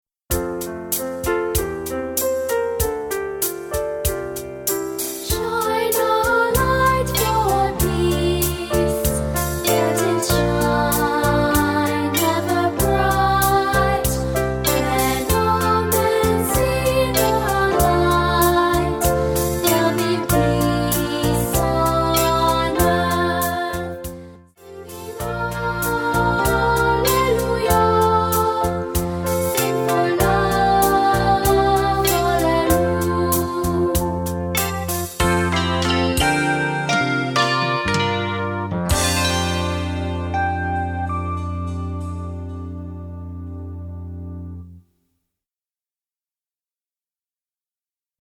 piano/vocal arrangement